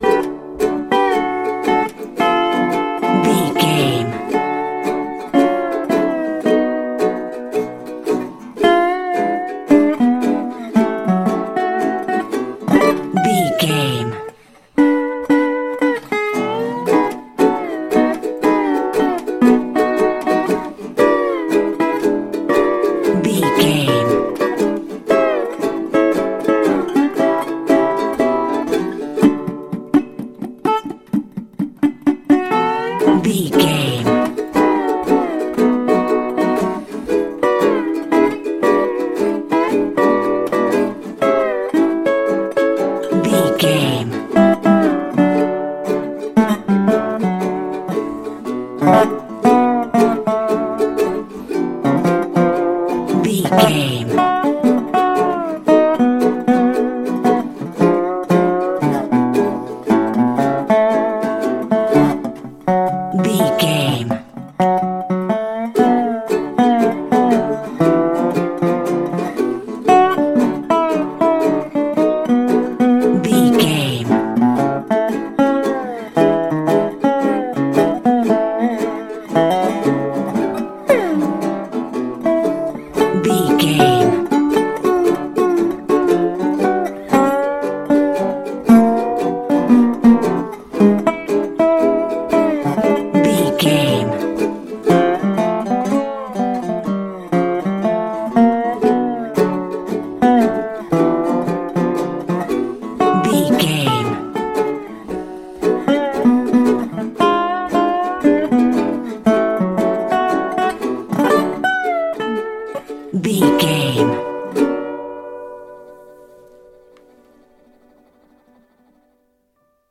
Uplifting
Ionian/Major
D
acoustic guitar
bass guitar
ukulele
slack key guitar